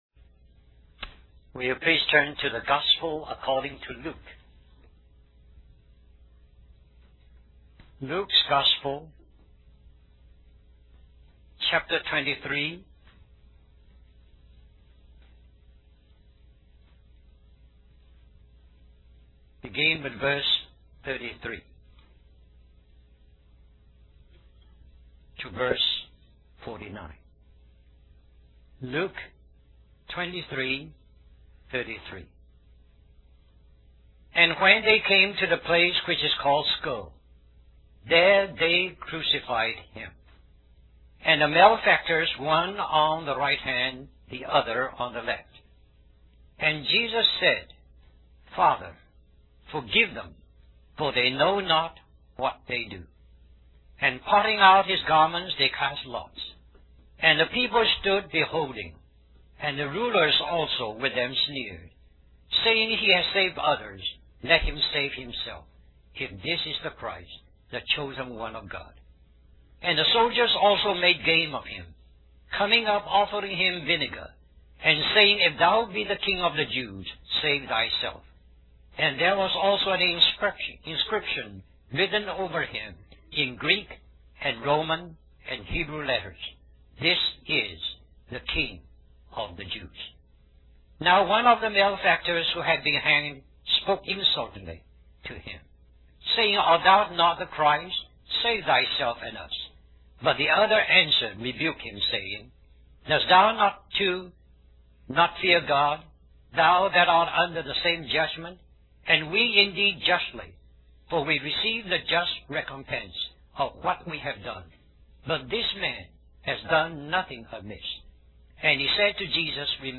1995 Richmond, Virginia, US Stream or download mp3 Summary This message is also printed in booklet form under the title